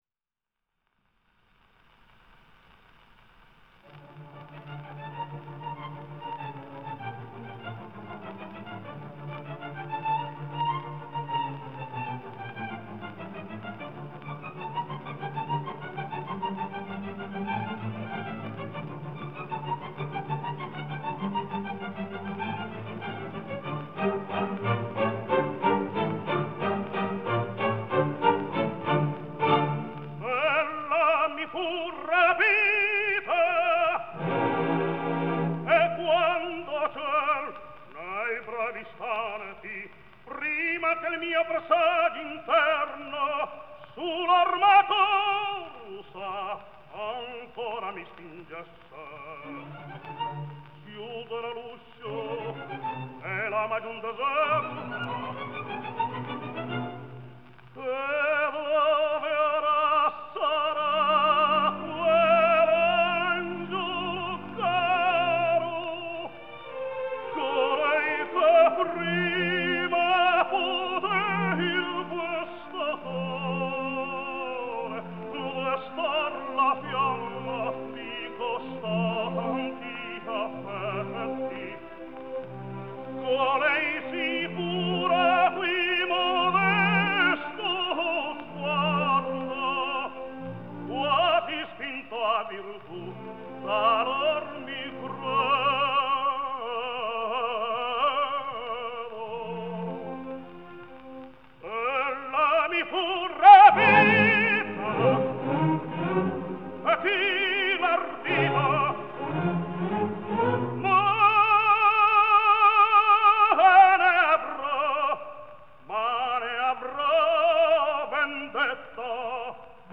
124 года со дня рождения испанского певца (тенор) Антонио Кортиса (Antonio Cortis)